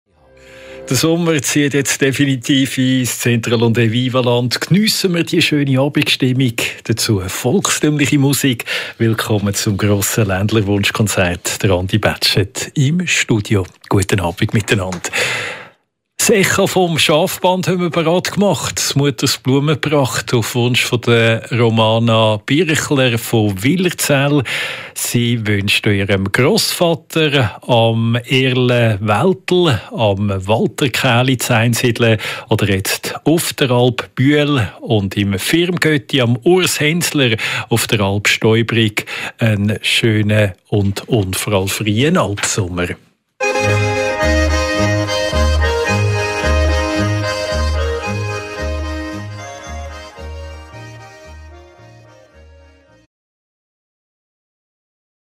Sponsoring Ländlerzmorge